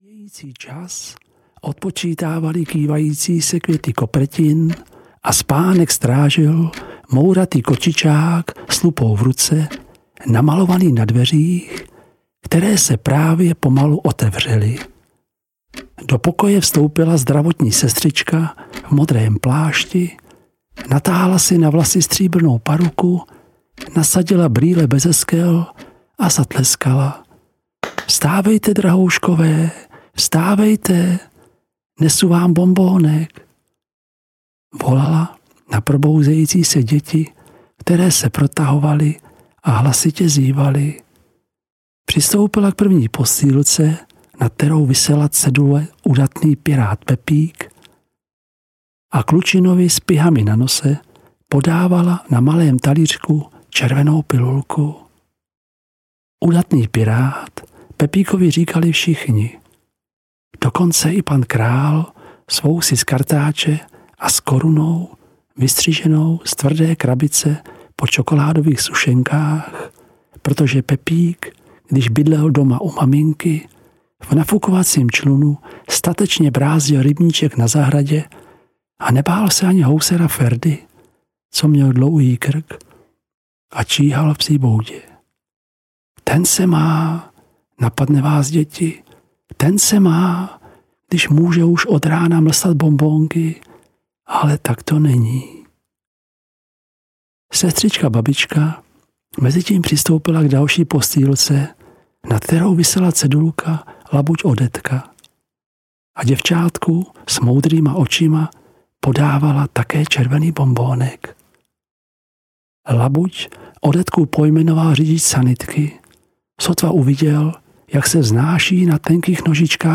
Labuť Odetka audiokniha
Ukázka z knihy